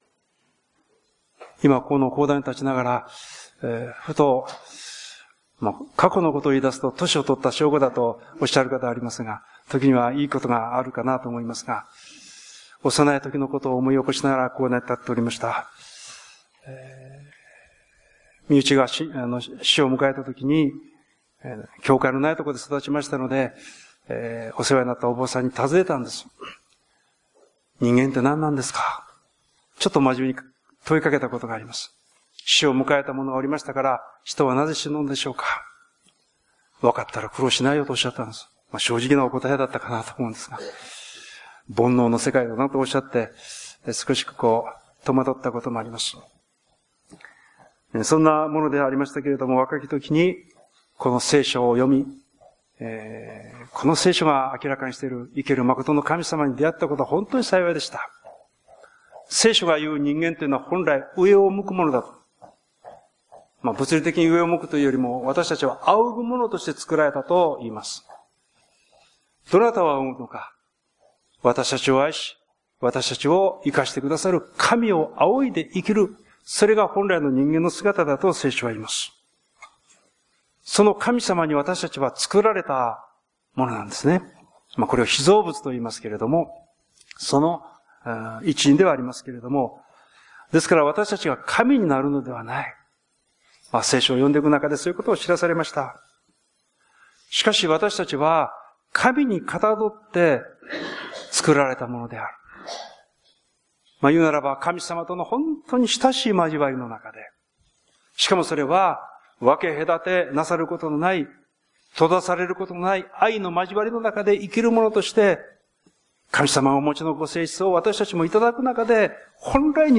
宣教題 「恐れないでただ信じなさい」 宣 教